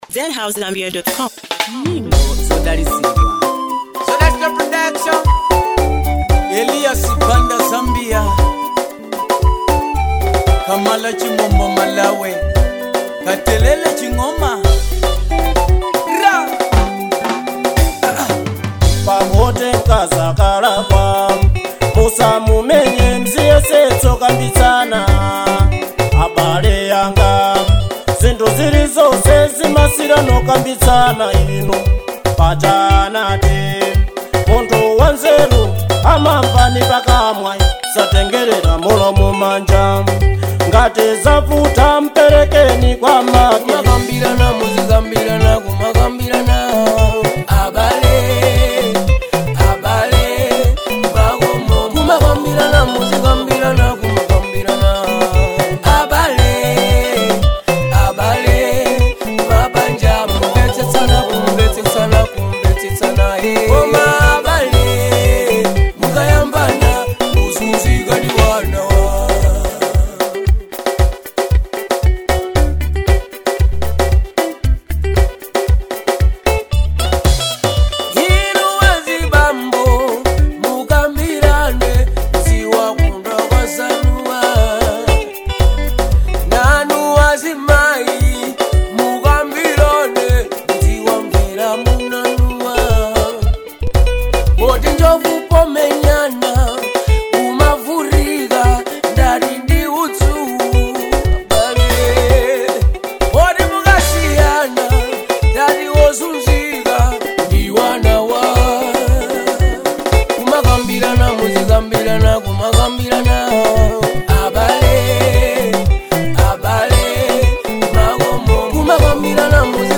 authentic Zambian vibes